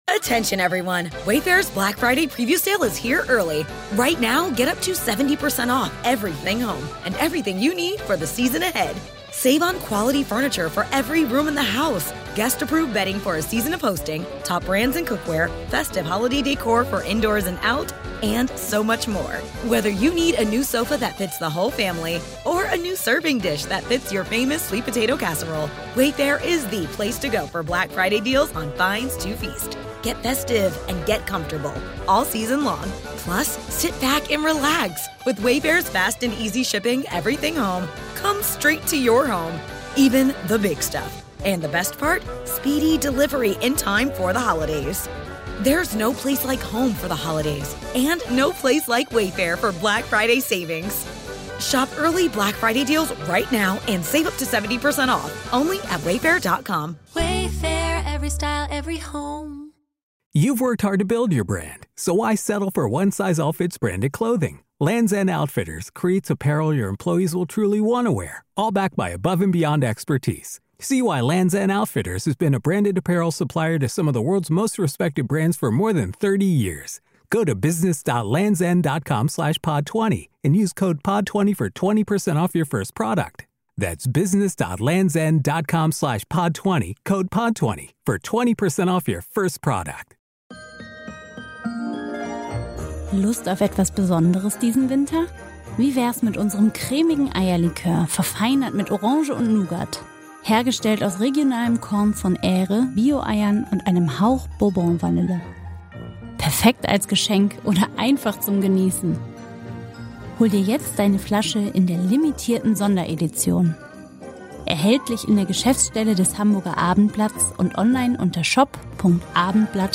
Sie erwarten unterhaltsame, nachdenkliche und natürlich sehr musikalische fünf Minuten.